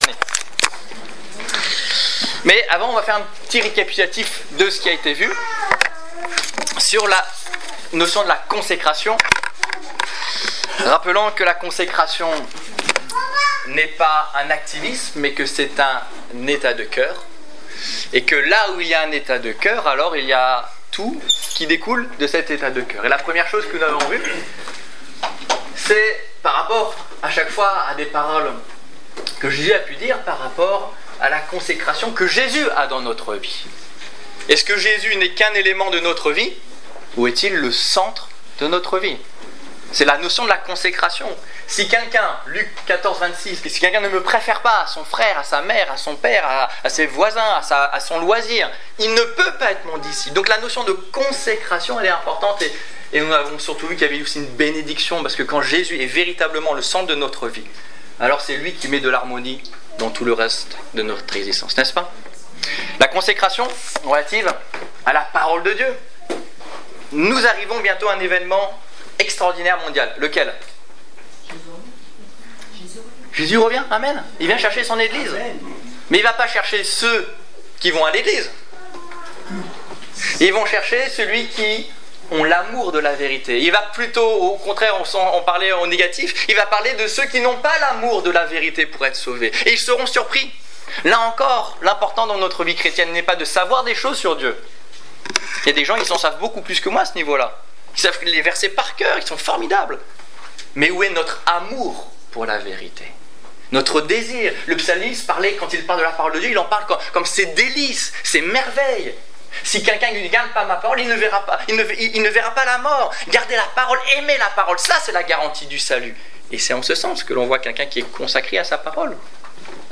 - Mon langage Détails Prédications - liste complète Culte du 28 juin 2015 Ecoutez l'enregistrement de ce message à l'aide du lecteur